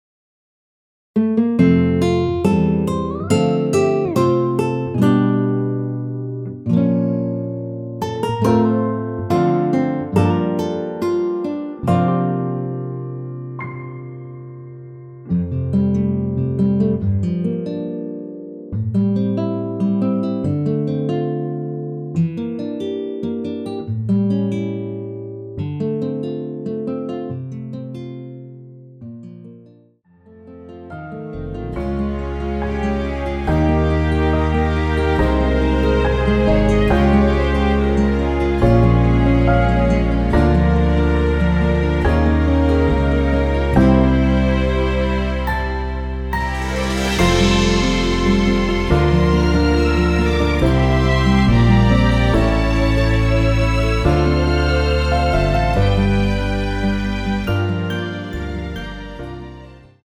앨범 | O.S.T
앞부분30초, 뒷부분30초씩 편집해서 올려 드리고 있습니다.
중간에 음이 끈어지고 다시 나오는 이유는